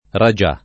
vai all'elenco alfabetico delle voci ingrandisci il carattere 100% rimpicciolisci il carattere stampa invia tramite posta elettronica codividi su Facebook ragià [ ra J#+ ] (raro ragia [ r #J a ]; fr. e ingl. rajah ) s. m. («principe indiano»)